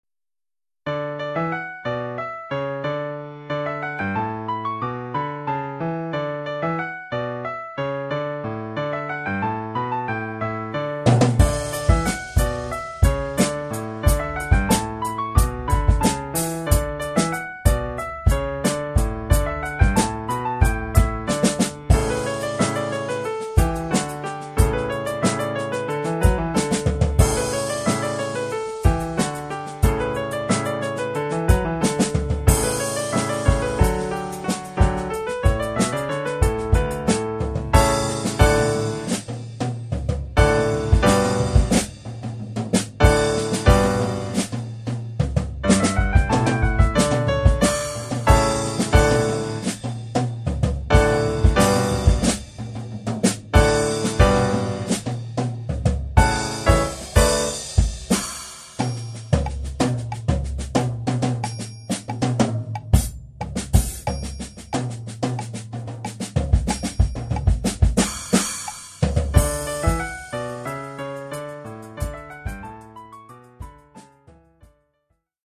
Percussions (batterie)
Oeuvre pour batterie et piano.